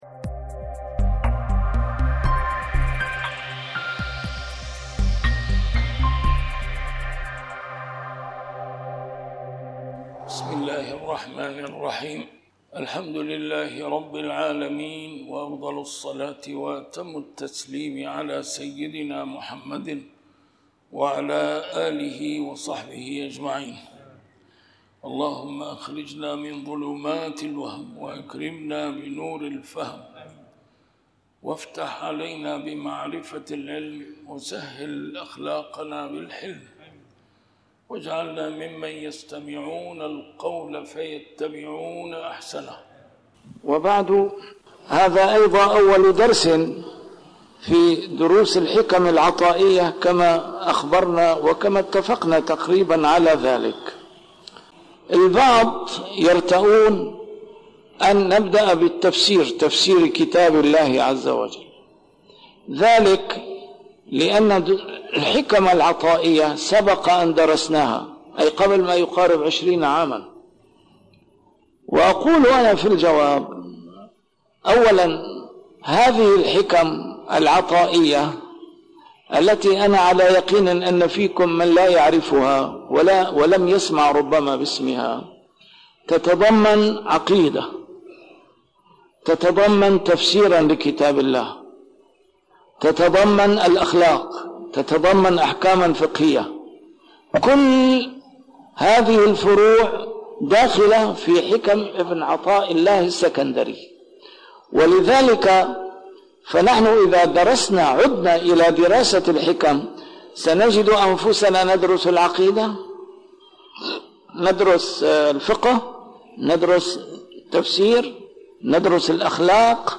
A MARTYR SCHOLAR: IMAM MUHAMMAD SAEED RAMADAN AL-BOUTI - الدروس العلمية - شرح الحكم العطائية - الدرس رقم 1 شرح الحكمة 1